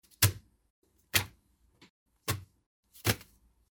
Звук купюр разных валют
4 варианта звука, когда пачку денег кладут на стол
4-varianta-kladut-pachku-na-stol.mp3